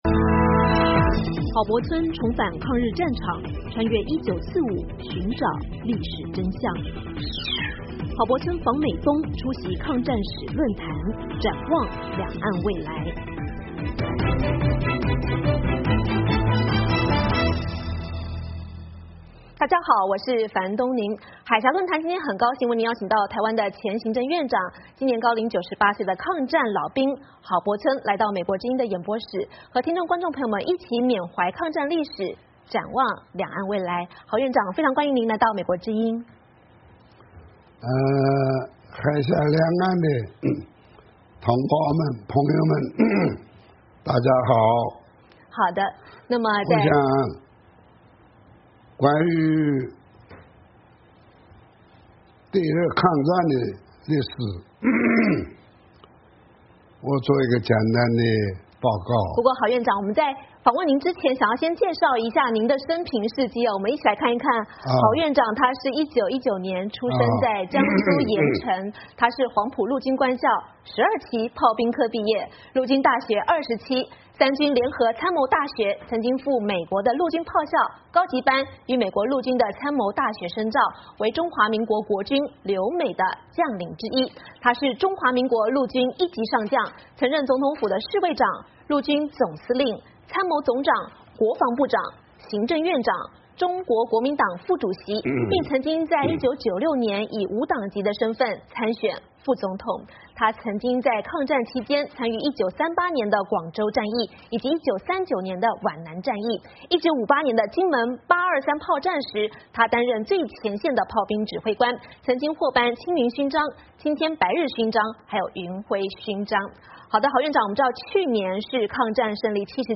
海峡论谈:专访郝柏村 缅怀抗战历史